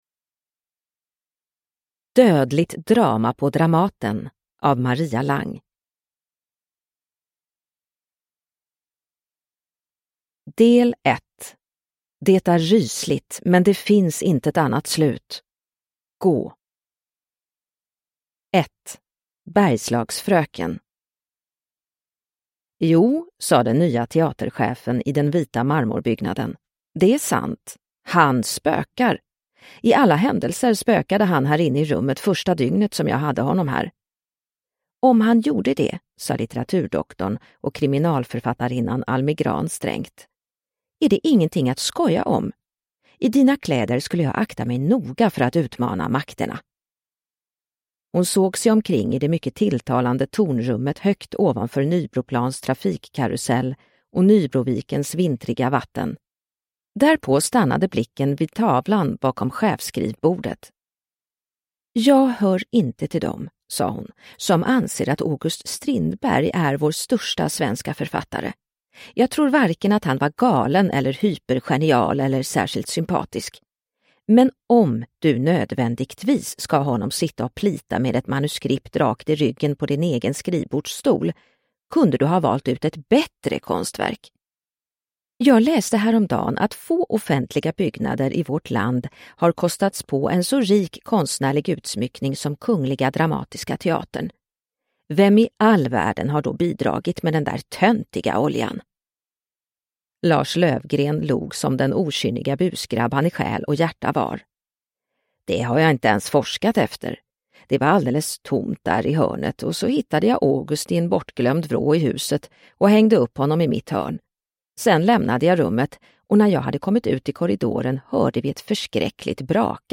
Dödligt drama på Dramaten – Ljudbok – Laddas ner